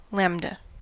Λ λάμβδα lamedh lambda [lambda] [ˈlamða] /ˈlæmdə/
En-us-lambda.ogg.mp3